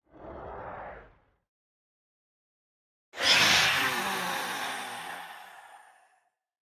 Minecraft Version Minecraft Version snapshot Latest Release | Latest Snapshot snapshot / assets / minecraft / sounds / mob / phantom / swoop2.ogg Compare With Compare With Latest Release | Latest Snapshot
swoop2.ogg